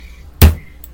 Listen & share punch the table and chill. you can use this for beat. 93 views.
punch the table and chill
punch-the-table-and-chill.mp3